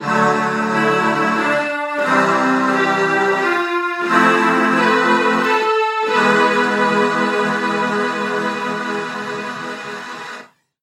Fantasy SFX
adventure fantasy game levelup piano rpg success victory sound effect free sound royalty free Gaming